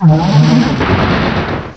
cry_not_thundurus.aif